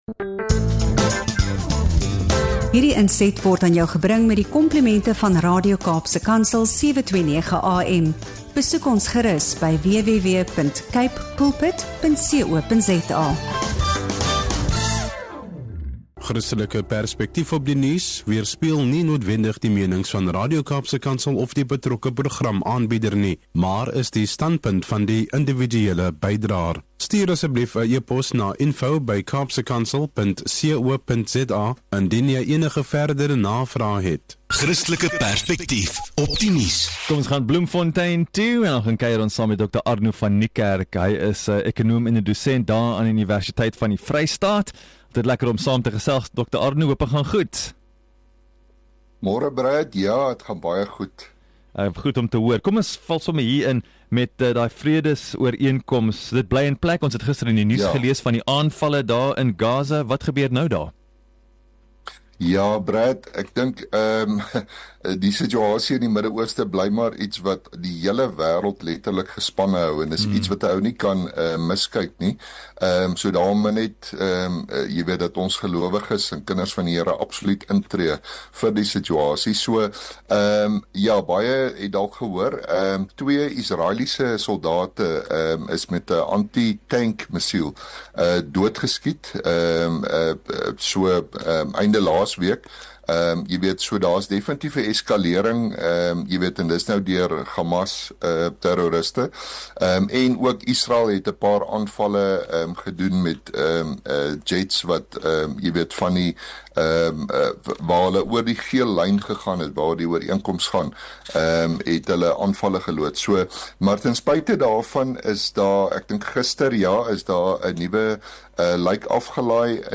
In hierdie onderhoud op Radio Kaapse Kansel